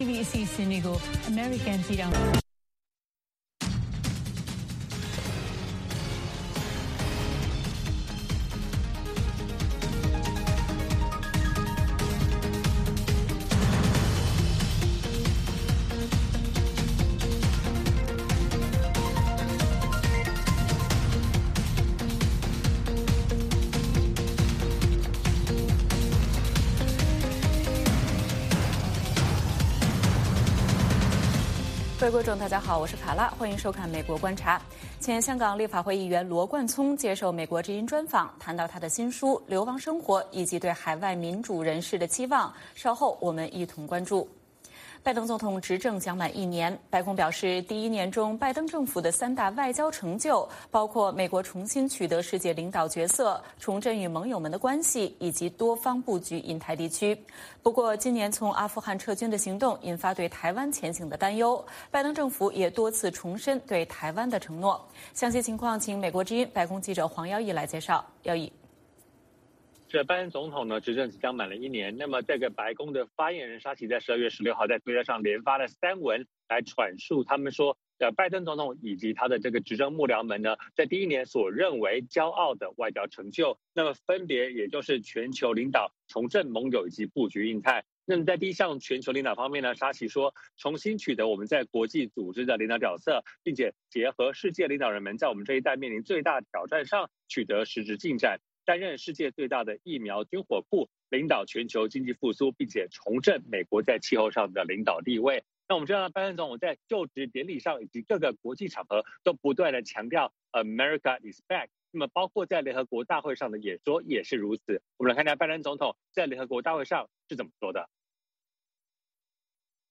北京时间早上6点广播节目，电视、广播同步播出VOA卫视美国观察。
节目邀请重量级嘉宾参与讨论。